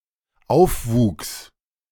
The related term Aufwuchs (German "surface growth" or "overgrowth", pronounced [ˈaʊ̯fˌvuːks]
De-aufwuchs.ogg.mp3